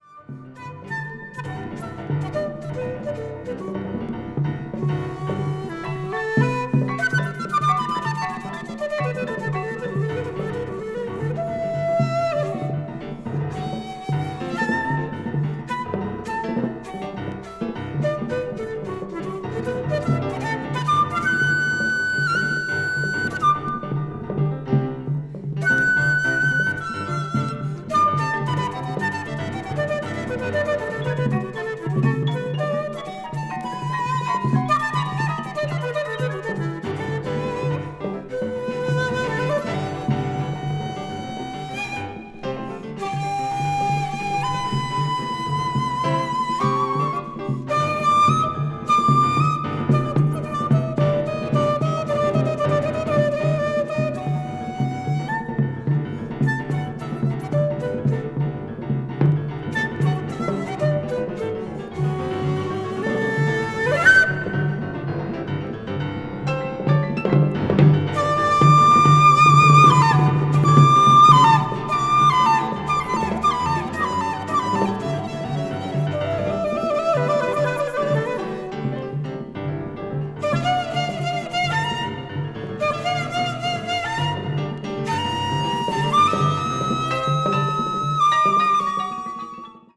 bamboo flute player
the great Japanese drummer and percussionist
one of Japan's leading jazz pianists